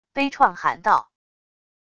悲怆喊道wav音频